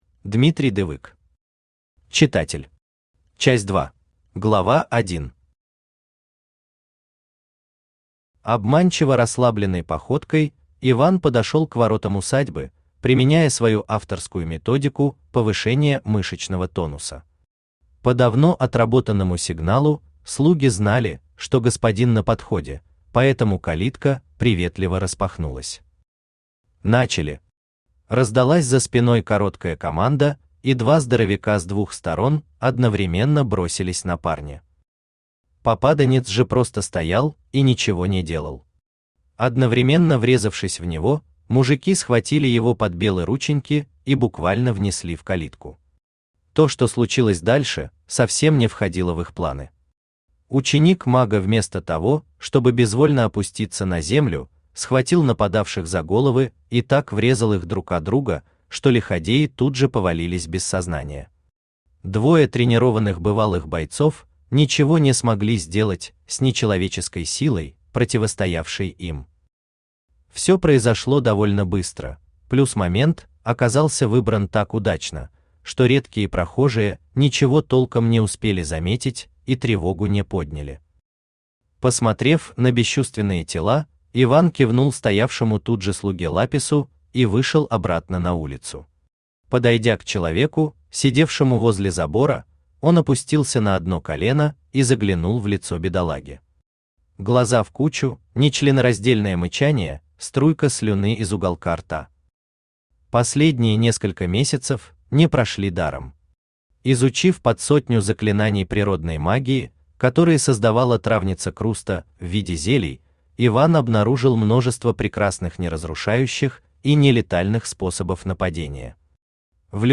Аудиокнига Читатель. Часть 2 | Библиотека аудиокниг
Aудиокнига Читатель. Часть 2 Автор Дмитрий Дывык Читает аудиокнигу Авточтец ЛитРес.